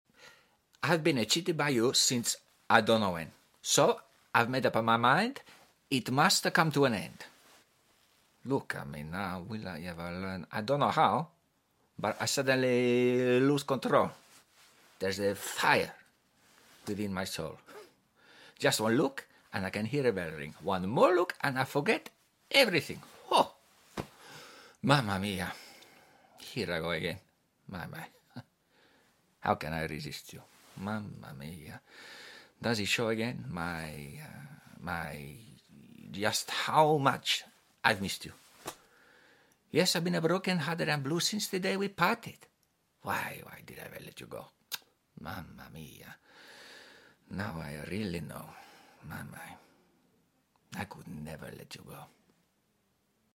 🎭 Dramatic Monologue: Mamma Mia sound effects free download